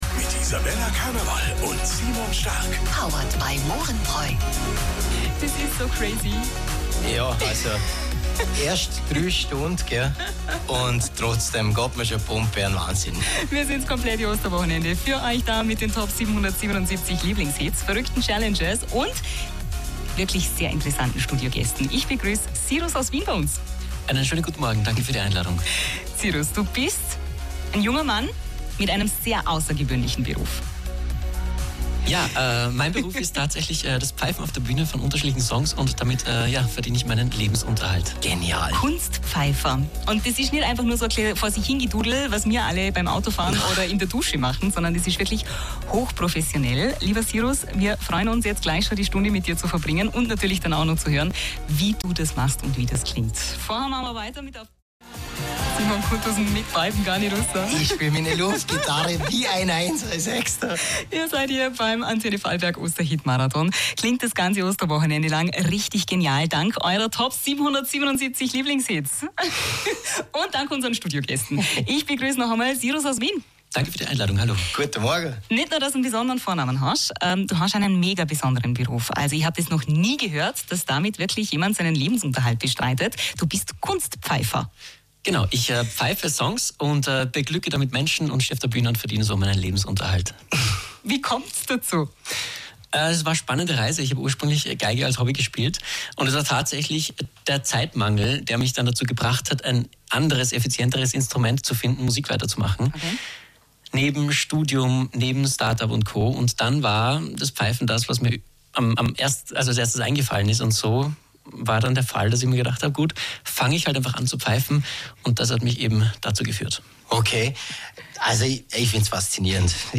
Radio Antenne Vorarlberg Interview – Osterfolge – 2025
Radio-Antenne-Vorarlberg-Interview-2025.mp3